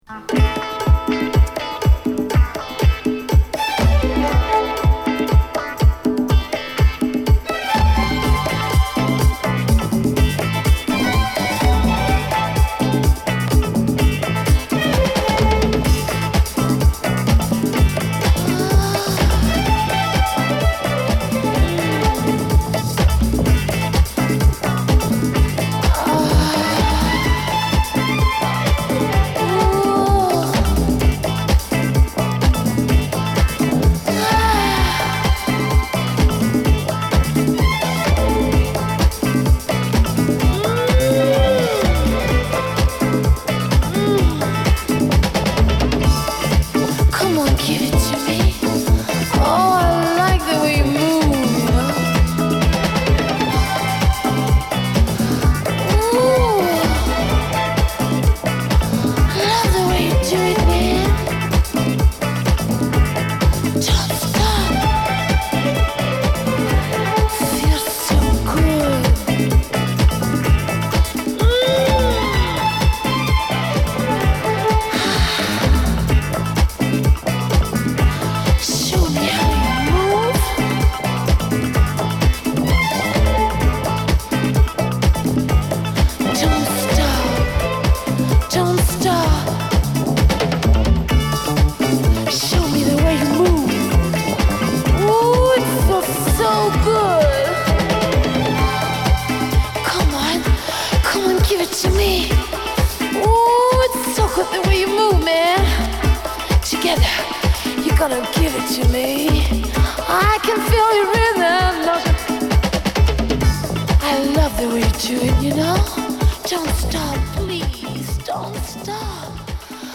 パーカッシヴなディスコ・グルーヴに絡む、ぶっ飛びまくりのオルガンとシンセの浮かび上がり方が半端ない
よりラグドでパーカッシヴで、ブラック・ファンキーなインストルメンタル・グルーヴが際立つ